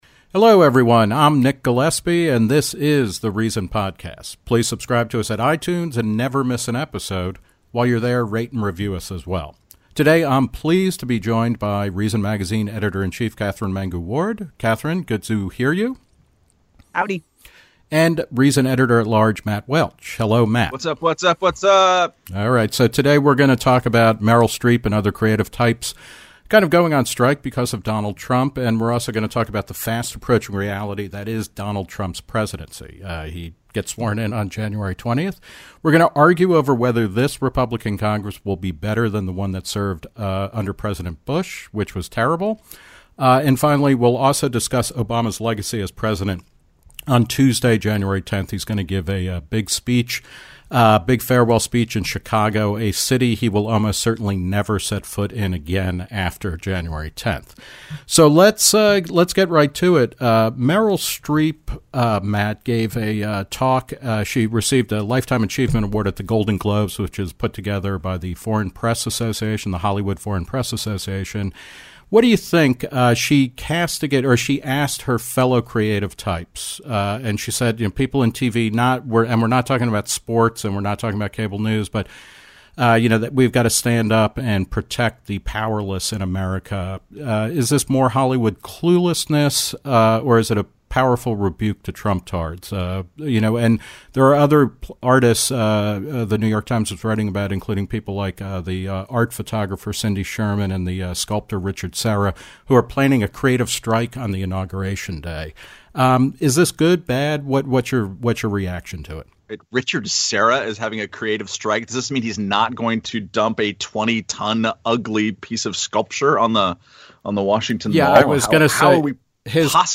discuss and debate